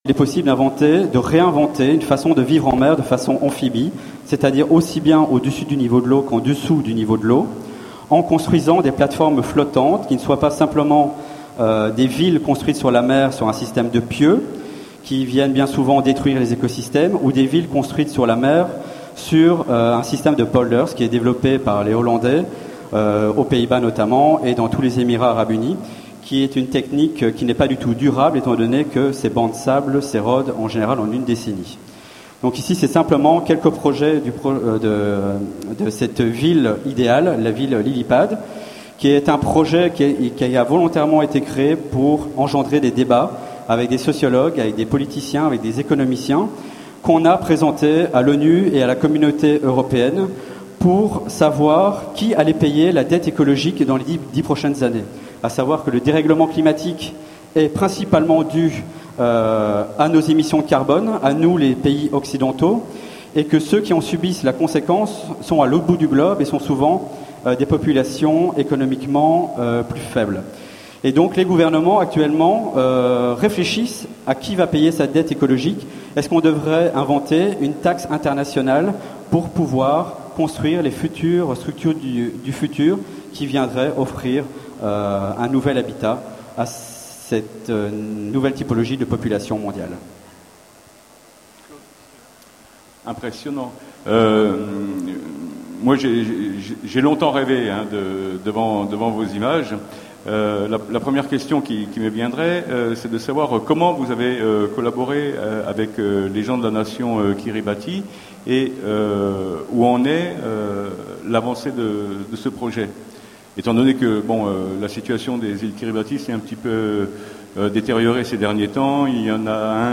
Utopiales 13 : Conférence Réfugiés climatiques